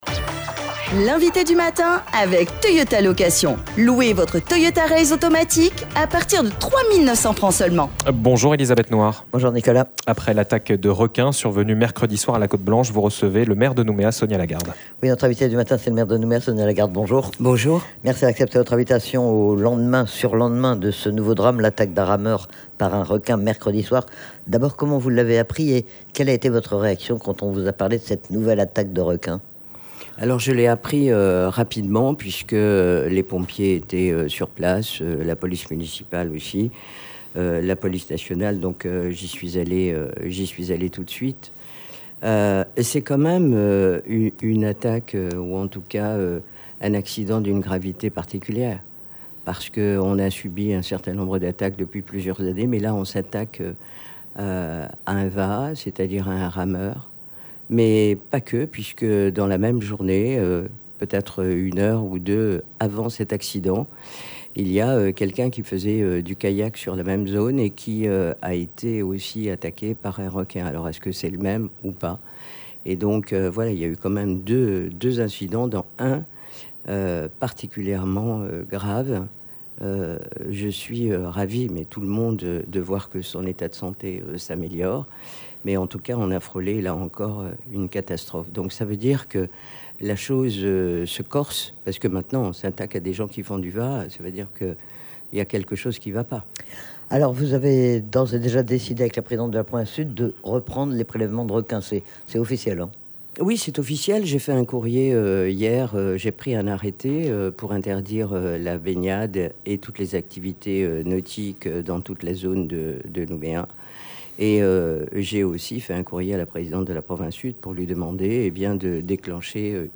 Sonia Lagarde maire de Nouméa était notre invitée du matin pour parler de la campagne de prélèvement de requin qui doit débuter après l'attaque de squale survenue mercredi soir.